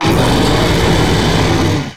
Cri de Zekrom dans Pokémon X et Y.